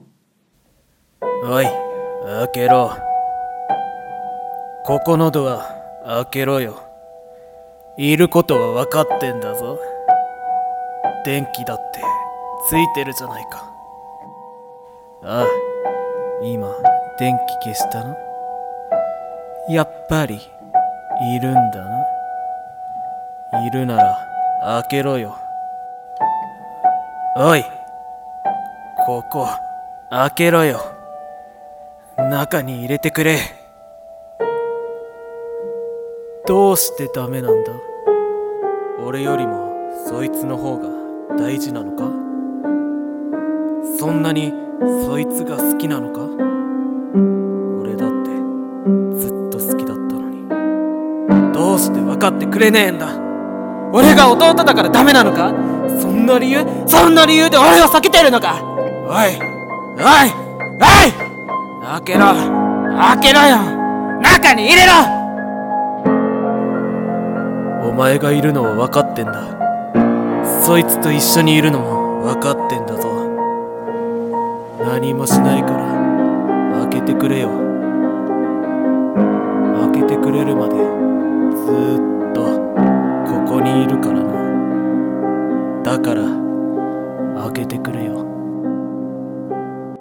閉ざされたドア 朗読者
BGM